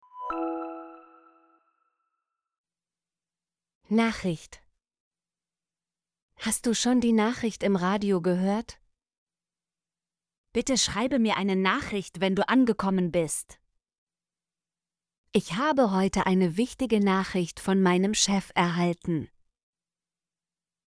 nachricht - Example Sentences & Pronunciation, German Frequency List